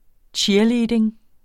Udtale [ ˈtjiɐ̯ˌliːdeŋ ]